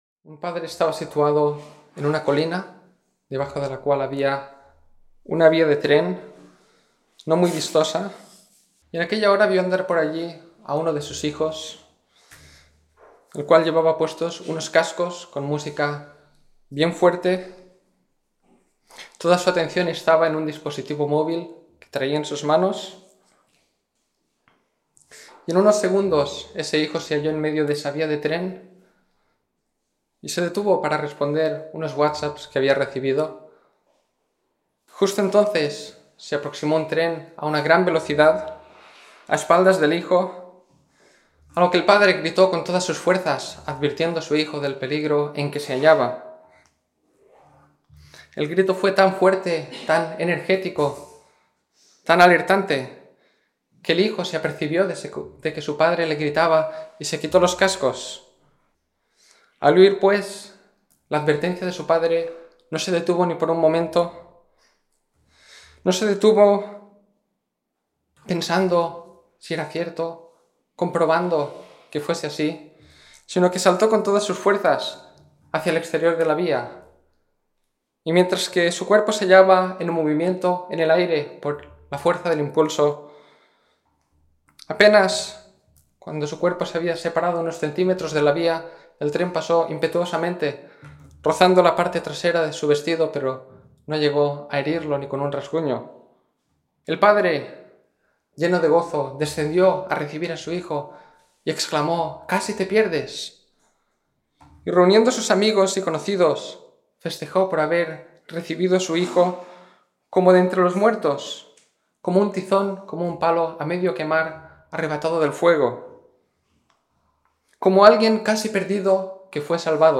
Domingo por la Mañana